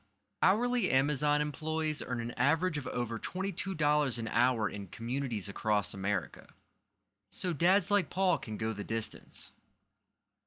Demos
Amazon Hourly Wage voiceover
Teen
Young Adult